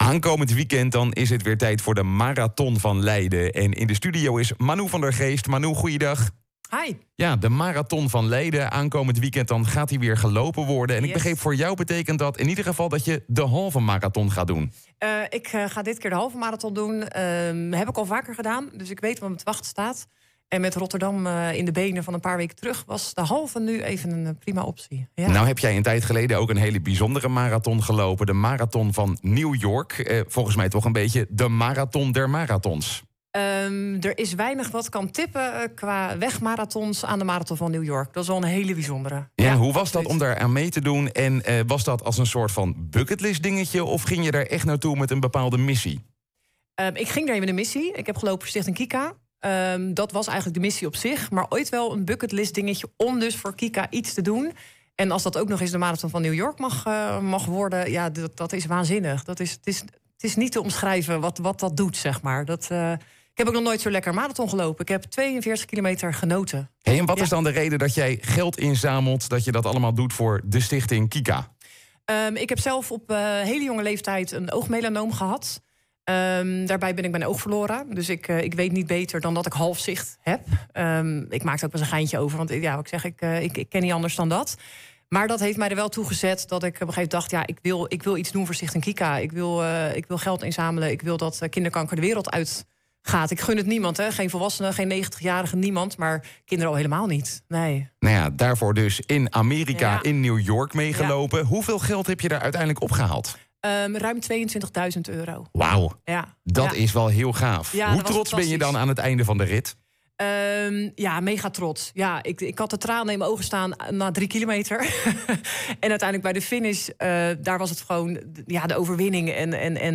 Presentator
in gesprek met